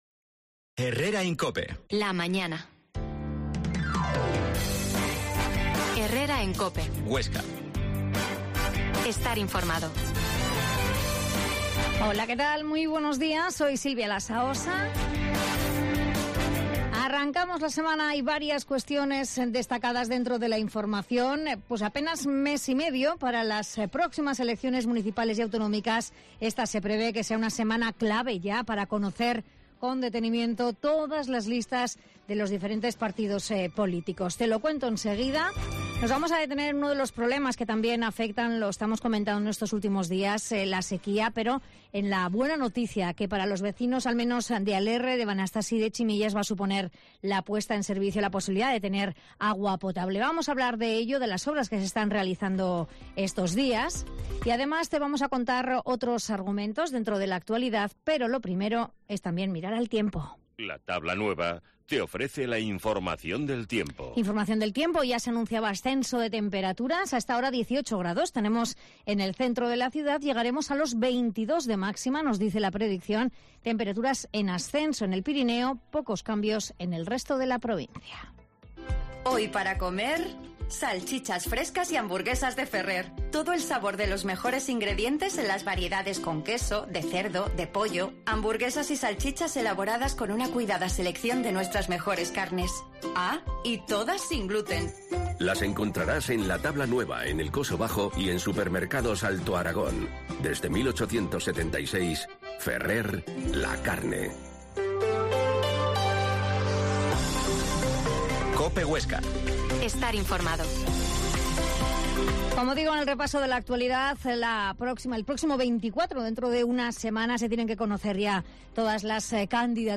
Herrera en COPE Huesca 12.50h Entrevista al alcalde de Alerre, Francisco José Santolaria